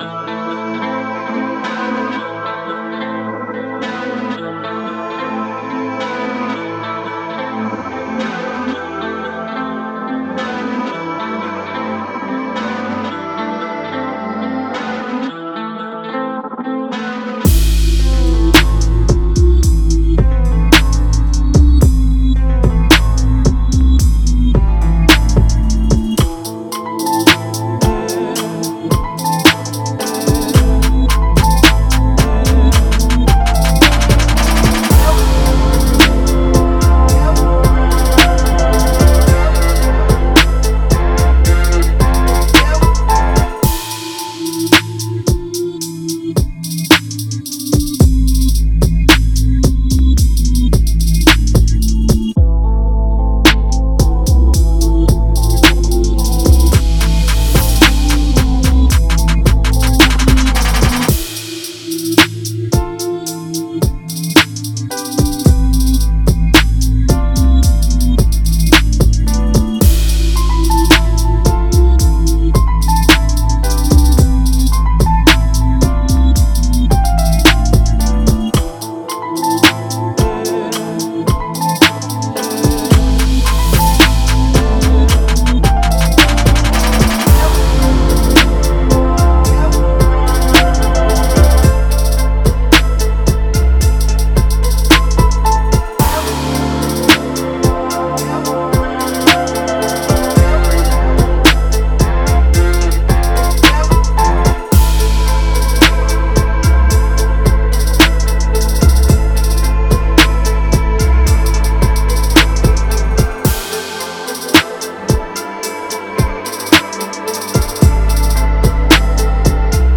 Trap piano melody instrumental beat.